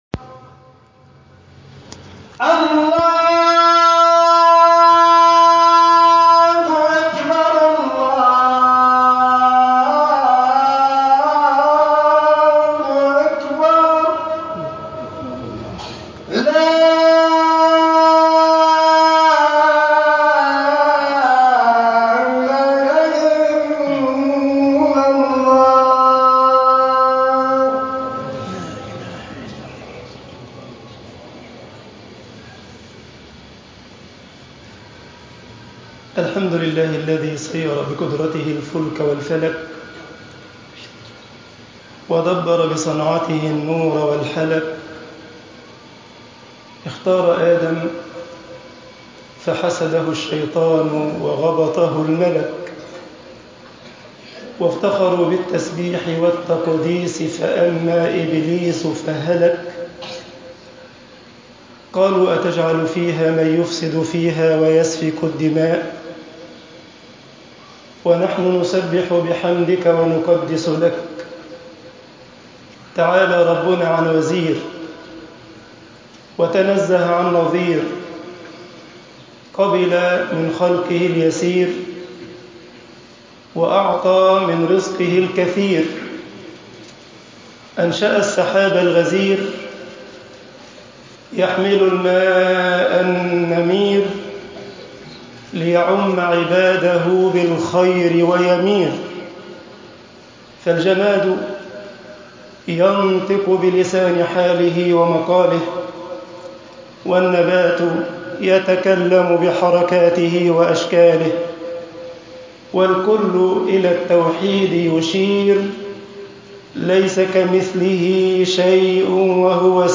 خطب الجمعة - مصر المؤمن أصول وفروع وثمار